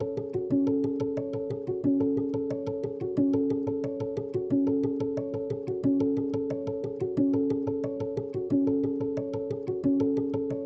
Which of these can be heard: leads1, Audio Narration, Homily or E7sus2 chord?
leads1